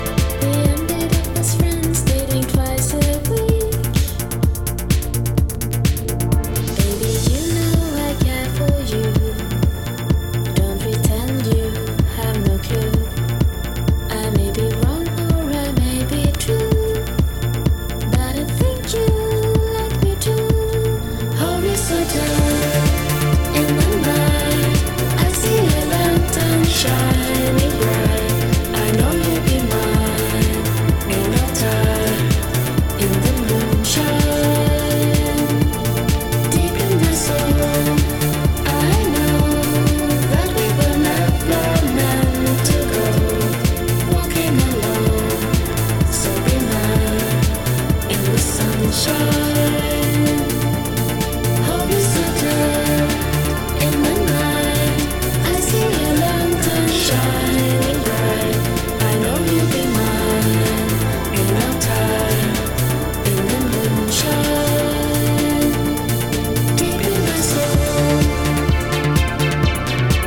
キュートなシンセポップ
深海アンビエント